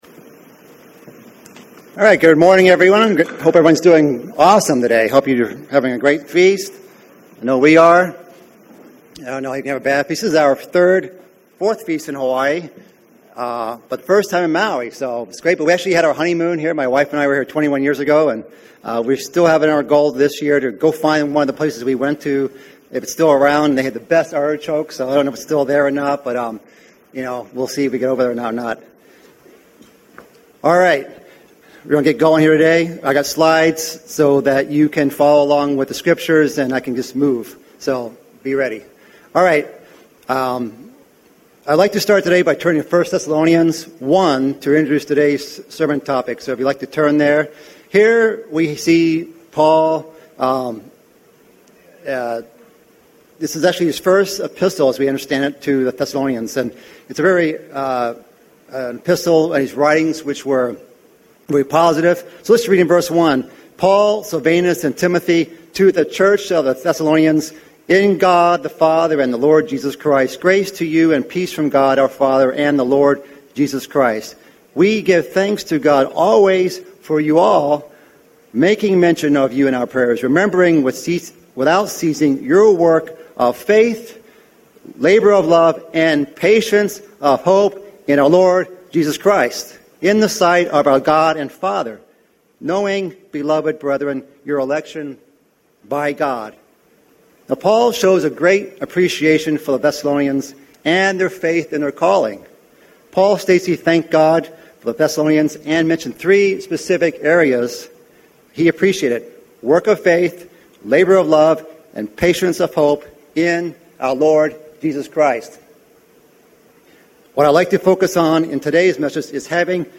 This sermon was given at the Maui, Hawaii 2015 Feast site.